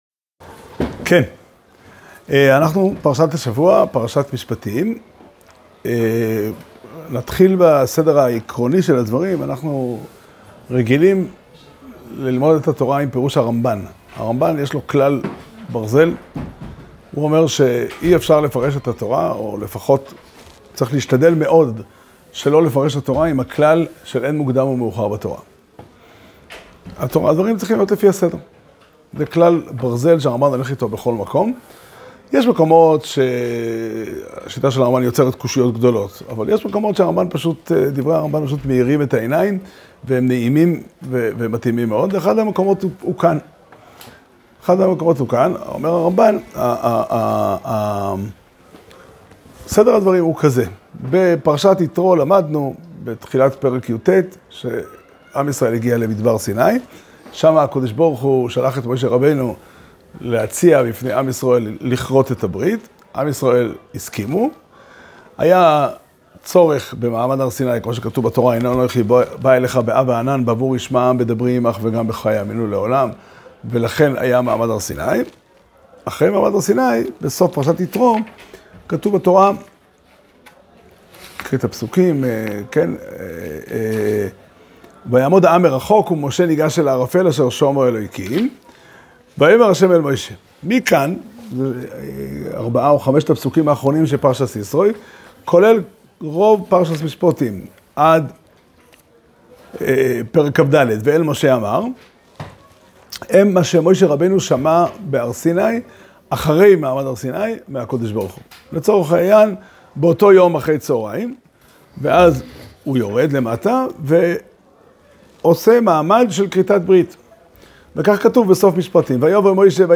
שיעור שנמסר בבית המדרש פתחי עולם בתאריך י"ט שבט תשפ"ה